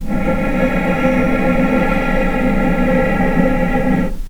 vc-C#4-pp.AIF